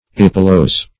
Epulose \Ep"u*lose`\, a.